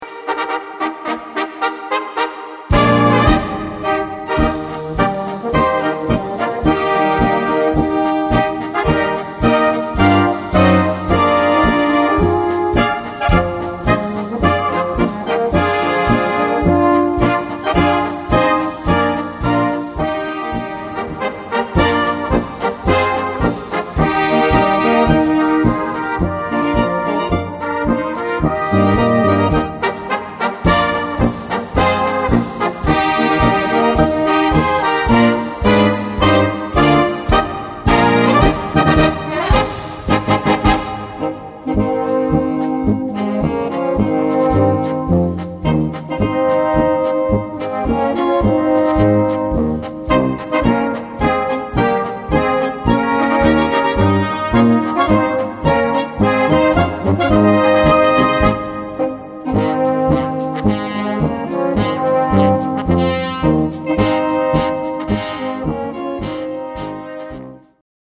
Gattung: Marsch für Blasorchester
Besetzung: Blasorchester